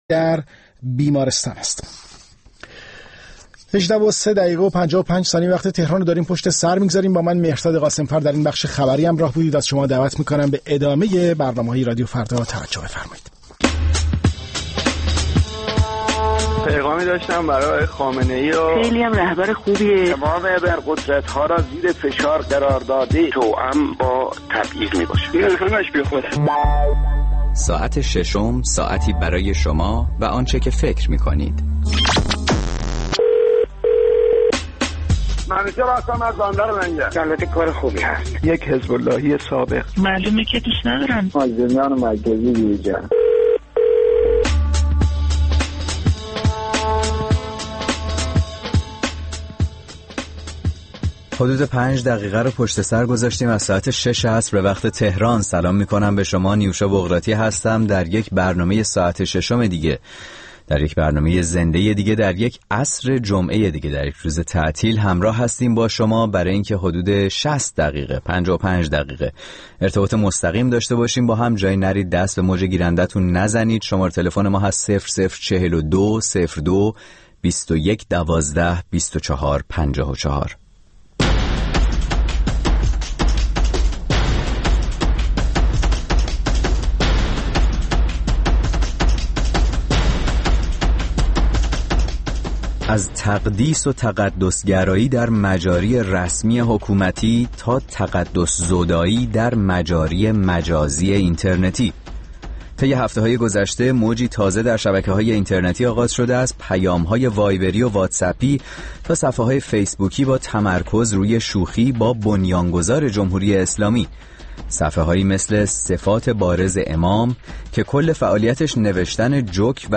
در کنار تماس‌های مستقیم مخاطبان رادیو فردا